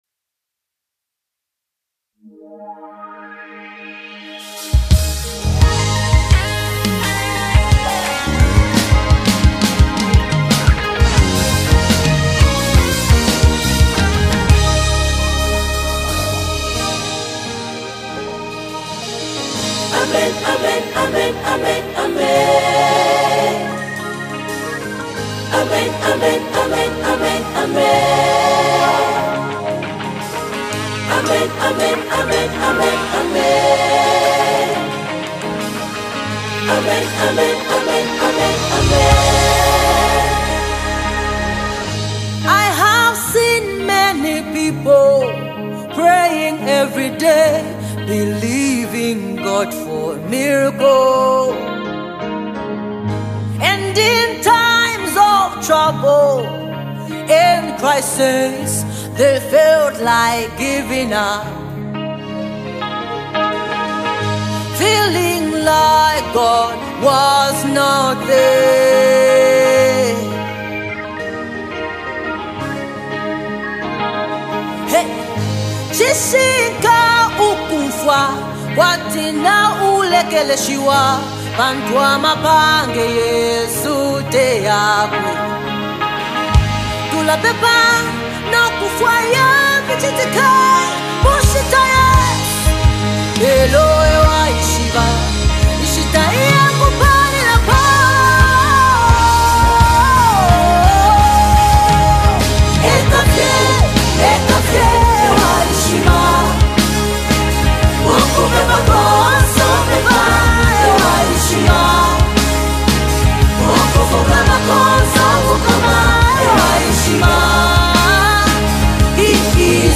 one of Zambia's most celebrated gospel singers
vocals are both commanding and comforting